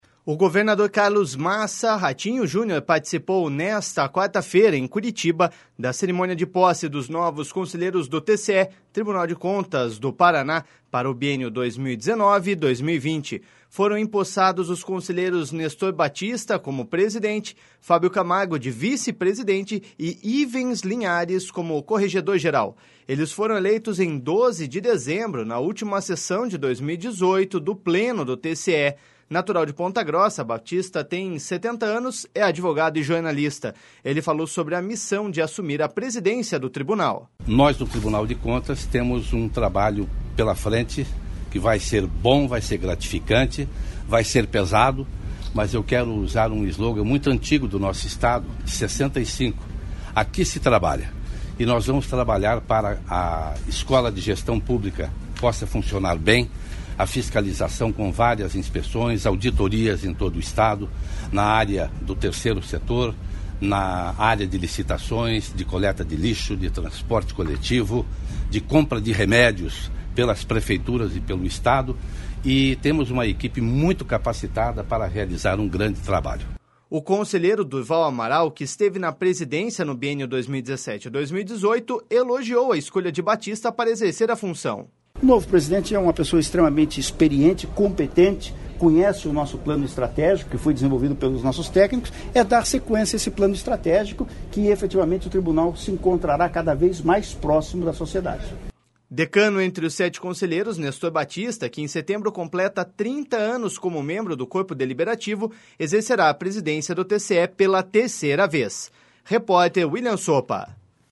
Ele falou sobre a missão de assumir a presidência do Tribunal.// SONORA NESTOR BAPTISTA.//
O conselheiro Durval Amaral, que esteve na presidência no biênio 2017-2018, elogiou a escolha de Baptista para exercer a função.// SONORA DURVAL AMARAL.//